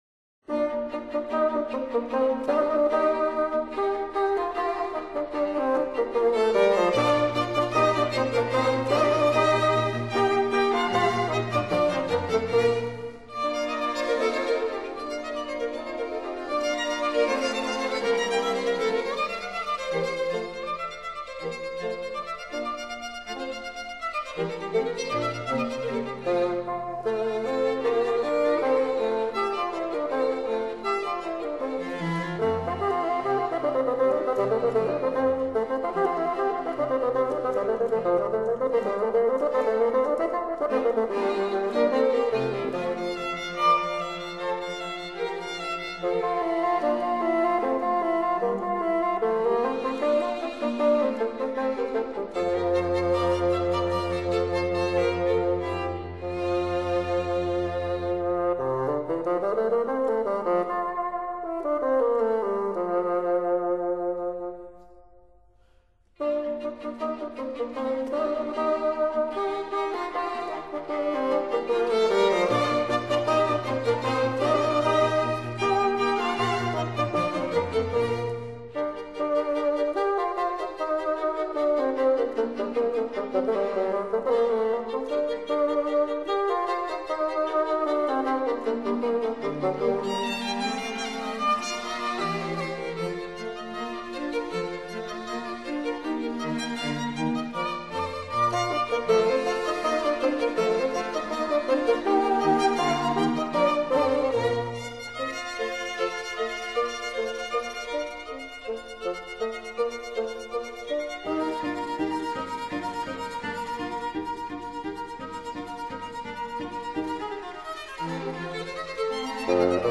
Rondo. Allegro    [0:03:56.00]